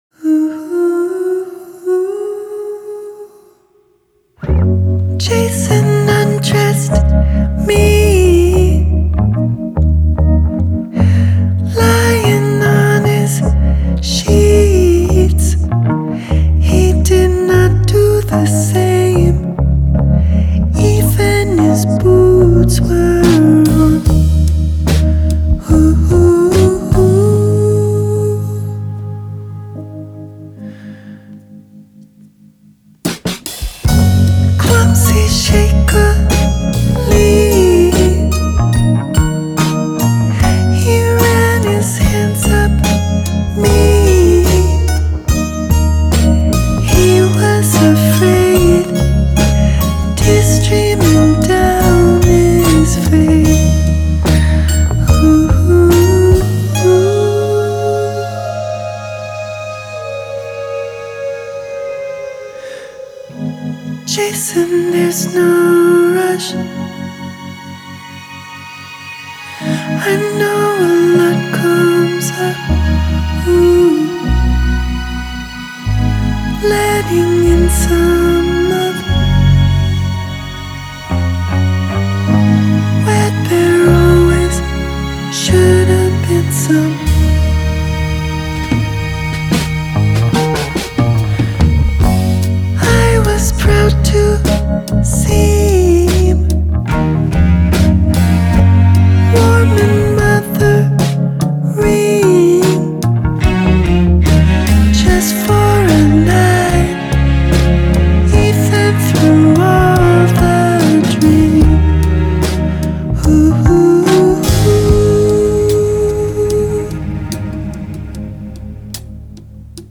Genre : Alt. Rock